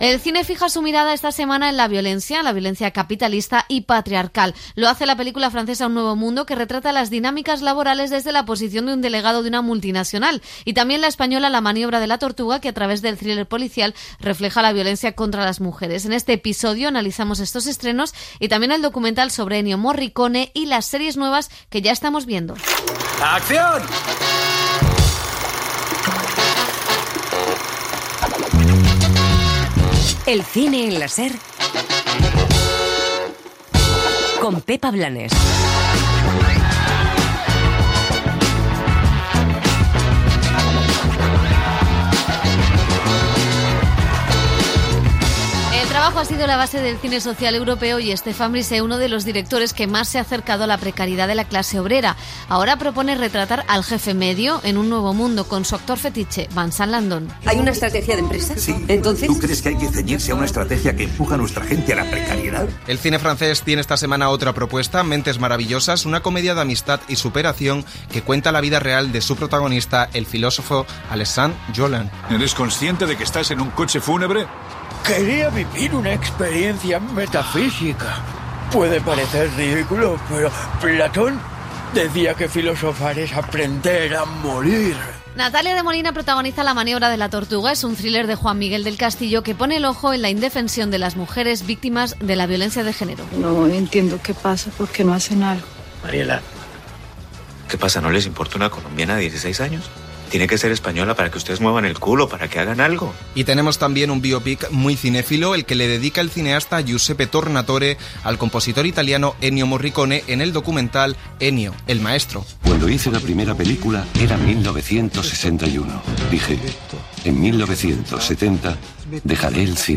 Estrenes de la setmana i sumari, careta del programa, resum d'estrenes de cinema de la setmana, tema musical, presentació d'algunes novetats de la setmana relacionades amb el cinema social